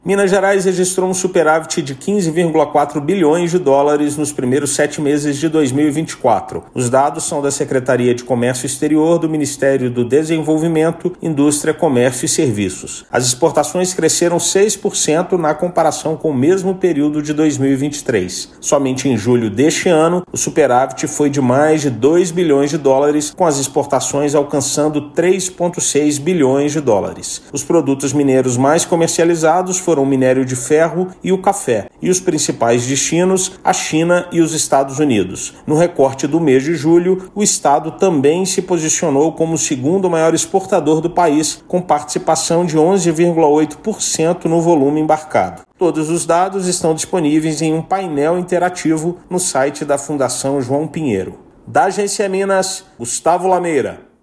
Dados atualizados do Comércio Internacional do estado estão disponíveis em painel interativo no site da FJP. Ouça matéria de rádio.